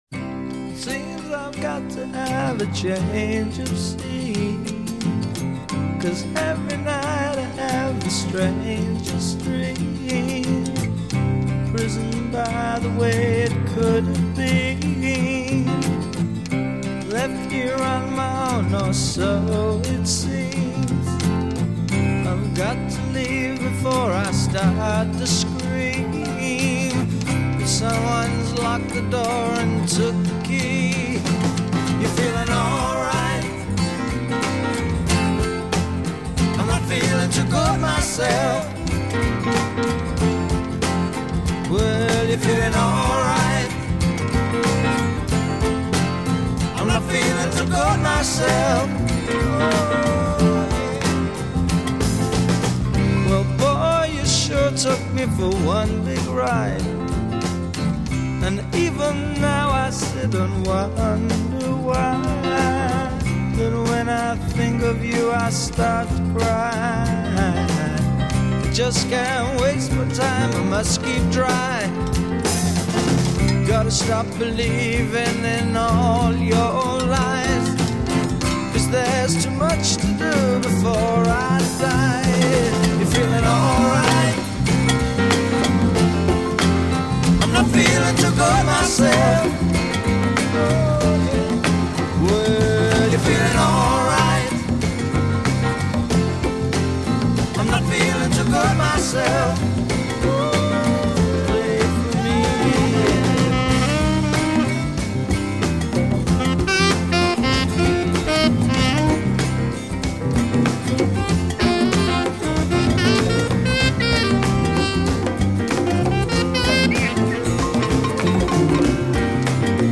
Refrain   Add harmony and drum kit. b
Verse   As above add cowbell and other percussion. c
Verse   Sax solo
Percussion subdued. d
Refrain   Repeat, build percussion, and fade. b